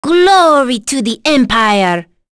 Rodina-Vox_Victory_b.wav